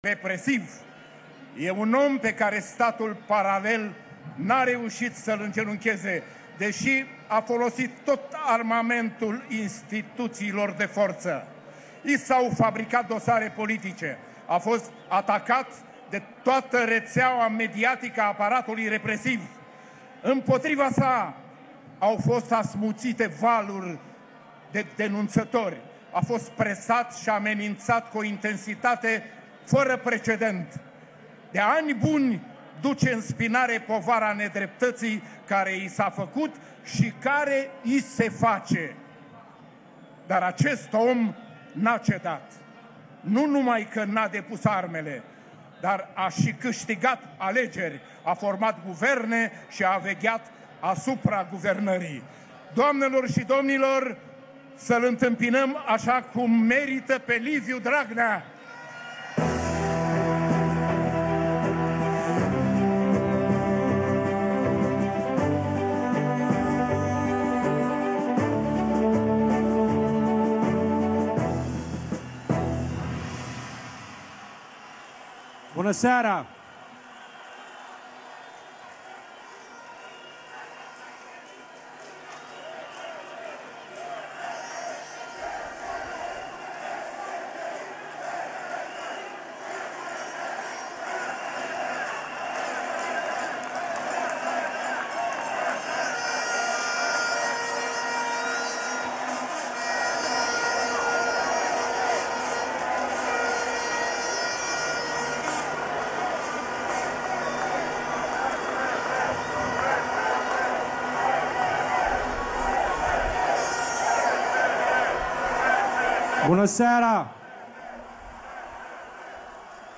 Discursurile liderilor PSD-ALDE – AUDIO
Coaliția de guvernare PSD-ALDE de la București organizează, sâmbătă seară, un miting de amploare în București.
Primul său îndemn e acela ca mulţimea să o aclame pe Simona Halep.
dragnea-discurs-miting-PSD-9-iunie.mp3